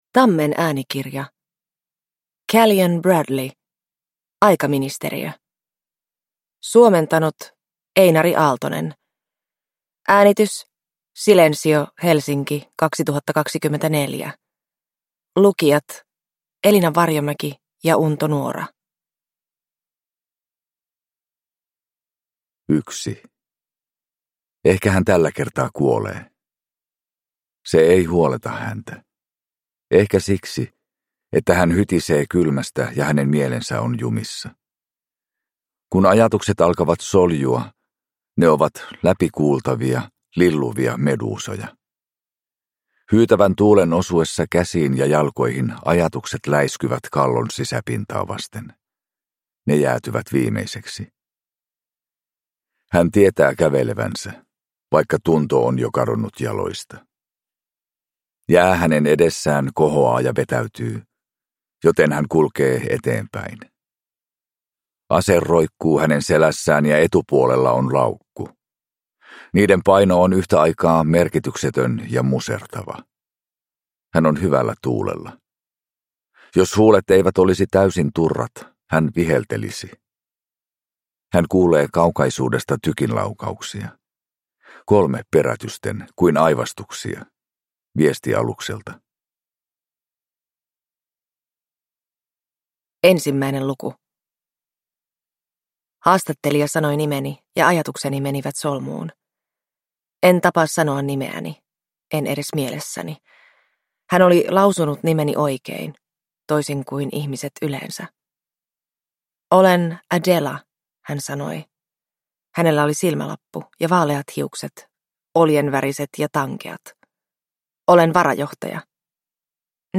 Aikaministeriö – Ljudbok